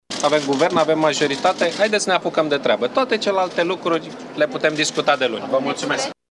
După anunțarea rezultatului votului moţiunii de cenzură, premierul a spus doar atât – „Avem majoritate, avem Guvern! Haideți să ne apucăm de treabă!”, a spus Victor Ponta.